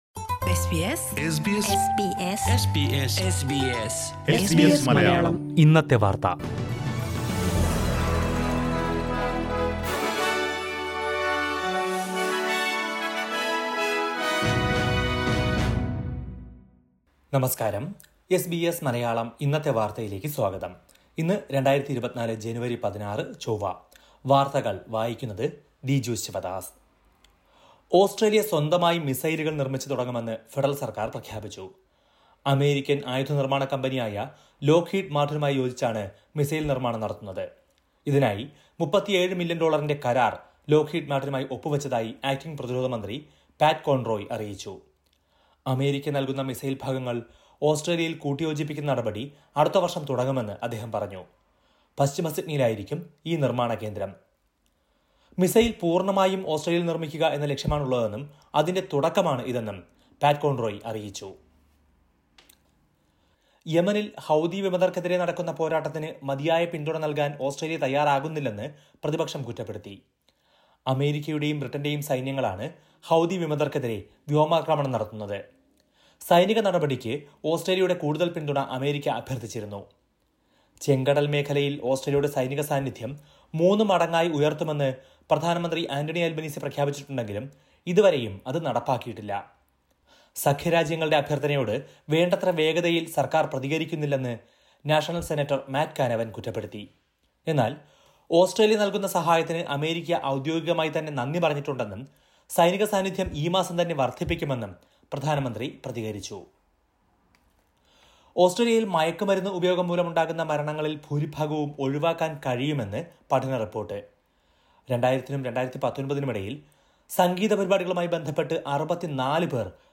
2024 ജനുവരി 16ലെ ഓസ്‌ട്രേലിയയിലെ ഏറ്റവും പ്രധാനപ്പെട്ട വാര്‍ത്തകള്‍ കേള്‍ക്കാം.